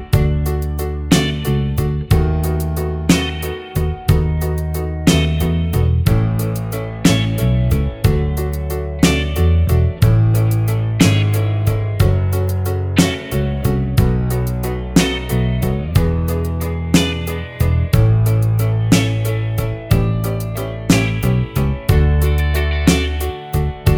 no Backing Vocals Soft Rock 2:59 Buy £1.50